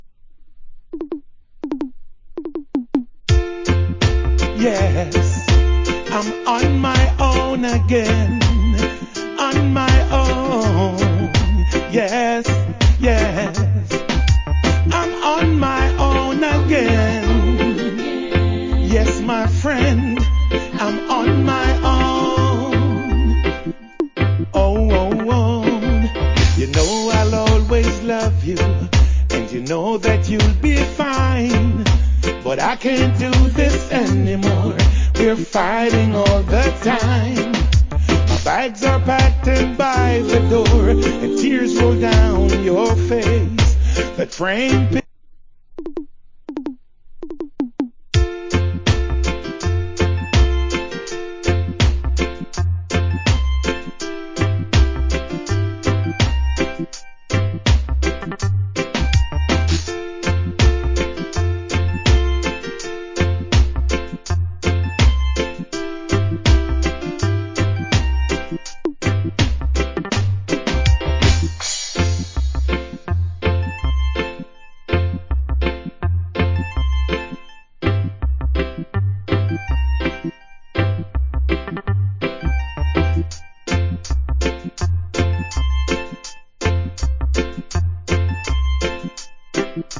コメント Nice Reggae Vocal. / Nice Dub.